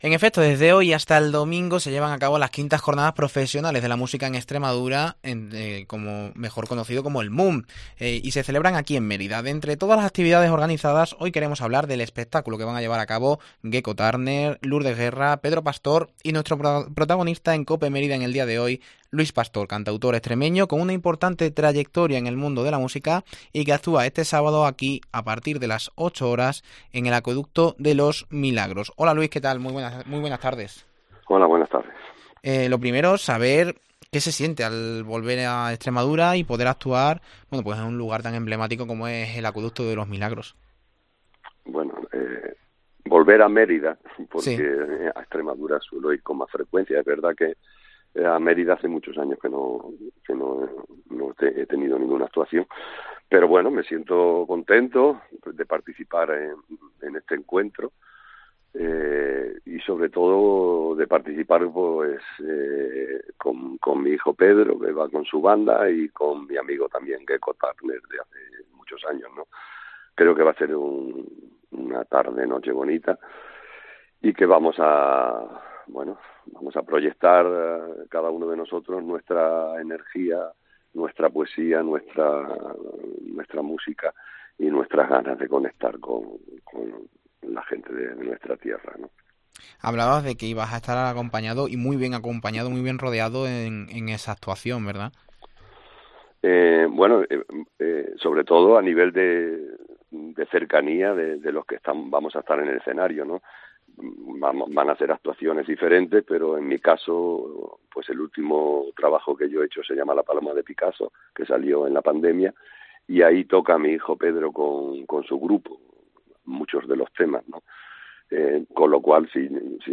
Entrevista a Luis Pastor